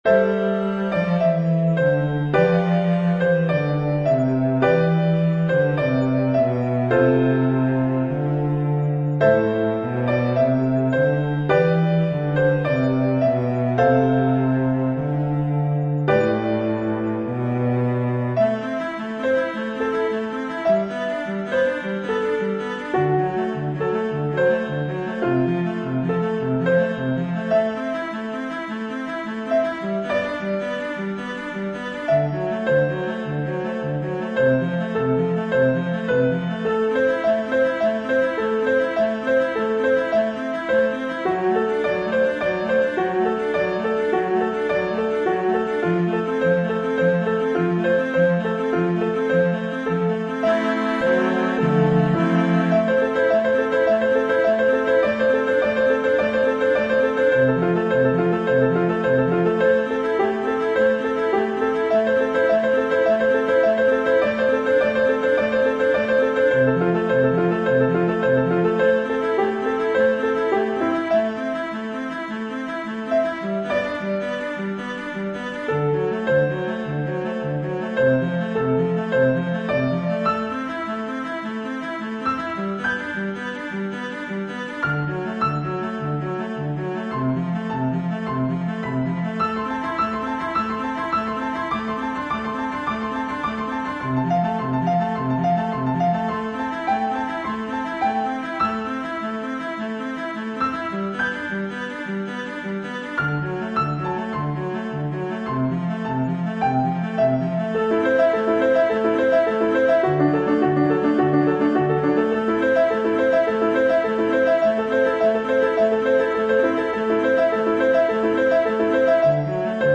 When I wrote it, I chose to make it a little repetitious, because I thought people would want to hear the hooks more than once, but it was sounding a little too monotonous, so I messed with it. I added some cello to the introduction. I like it, but it sounds a little dissonant. I think the piano samples Finale used may be slightly flat!